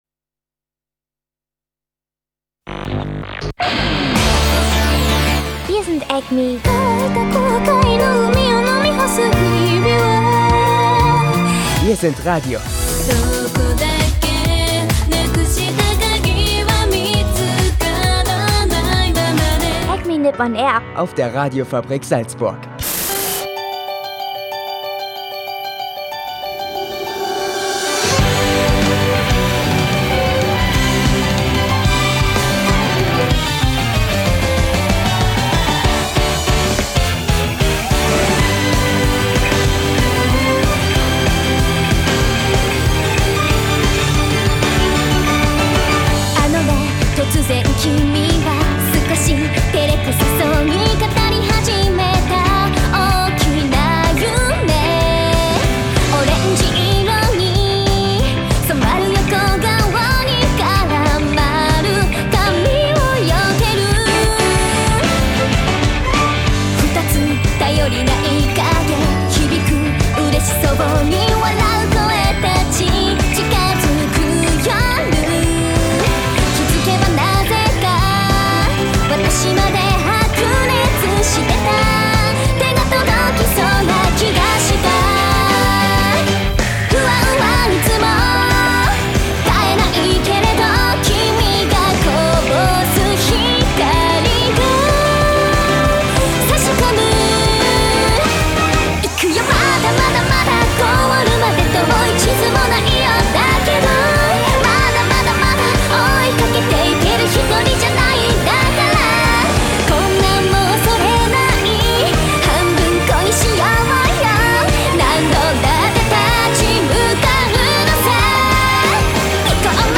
Außer halt: Anime-News zu besonders teuren Figuren, Anime für Mafia-Mitglieder Japan-News zu den Vorgängen um die bevorstehende Tennou-Abdankung und ein Städte-Ranking, dass Salzburg und Tokyo verbindet und viel Anime- und Japan-Pop-Musik Mehr